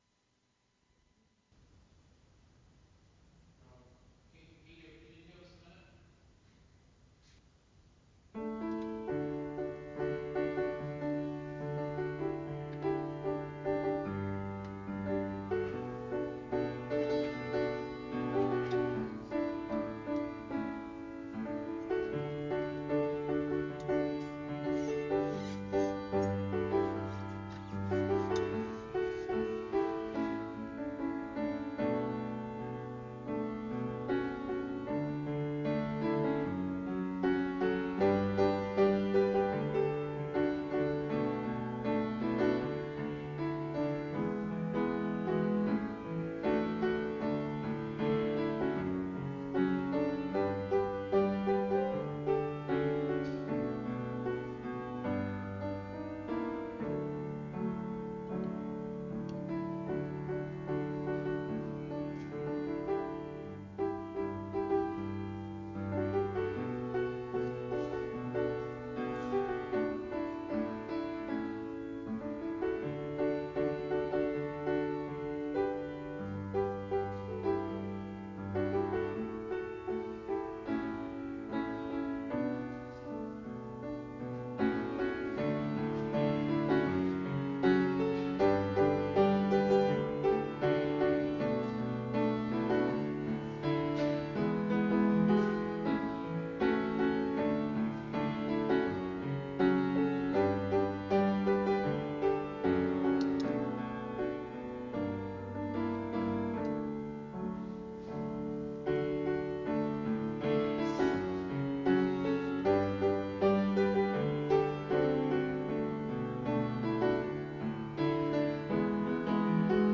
Sermons | Fellowship Christian Reformed Church of Toronto
Fellowship Church is pleased to offer this live service at 10AM via Zoom.